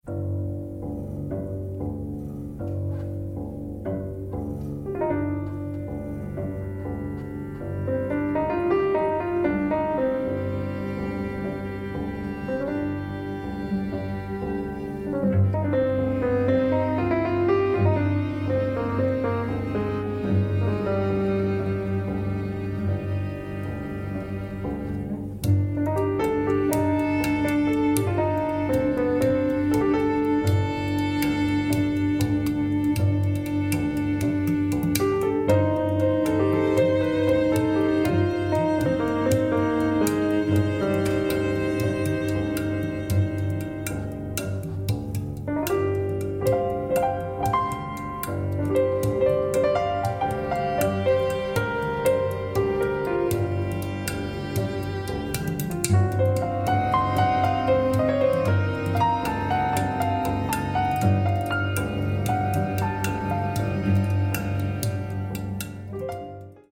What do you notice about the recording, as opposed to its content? Recorded October 1, 2024 at Miller Theatre, NYC